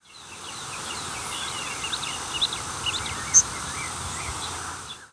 Chestnut-sided Warbler diurnal
Chestnut-sided Warbler diurnal flight calls